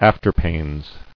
[af·ter·pains]